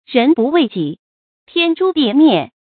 注音：ㄖㄣˊ ㄅㄨˋ ㄨㄟˊ ㄐㄧˇ ，ㄊㄧㄢ ㄓㄨ ㄉㄧˋ ㄇㄧㄝ ˋ